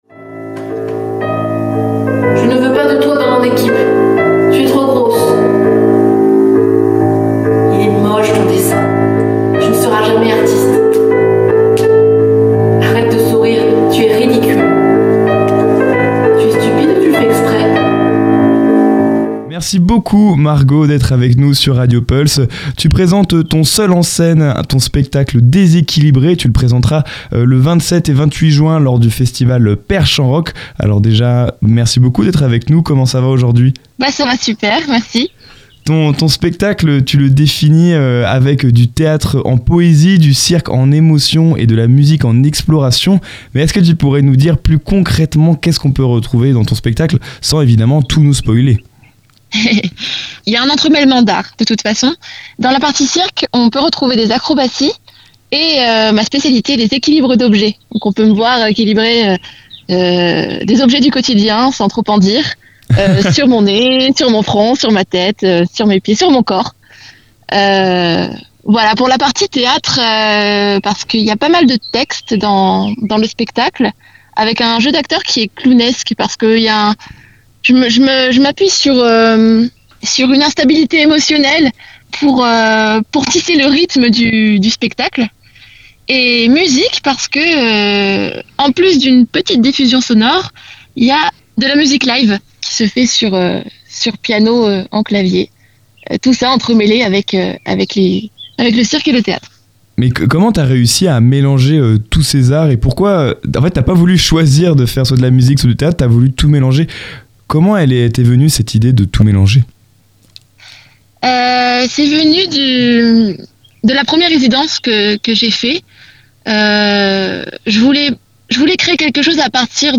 est venue répondre à nos questions sur RadioPulse autour de son spectacle